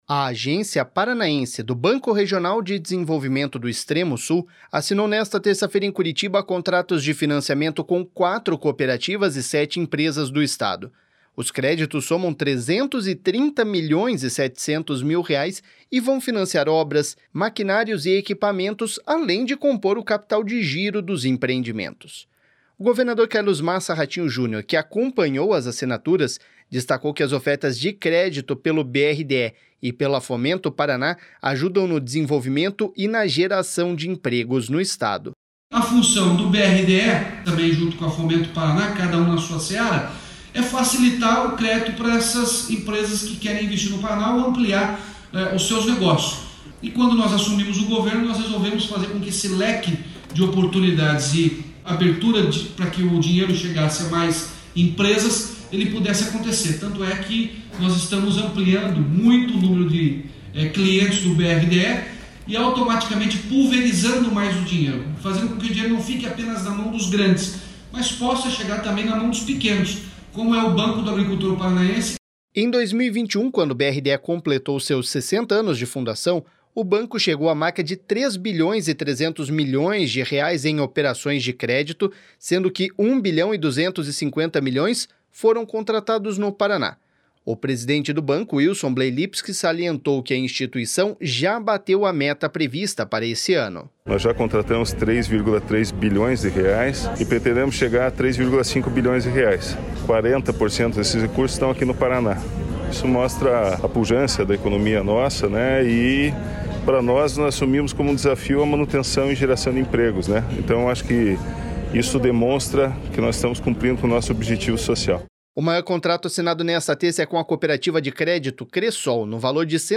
Os créditos somam 330 milhões e 700 mil reais, e vão financiar obras, maquinários e equipamentos, além de compor o capital de giro dos empreendimentos. O governador Carlos Massa Ratinho Junior, que acompanhou as assinaturas, destacou que as ofertas de crédito pelo BRDE e pela Fomento Paraná ajudam no desenvolvimento e na geração de empregos no Estado.// SONORA RATINHO JUNIOR.//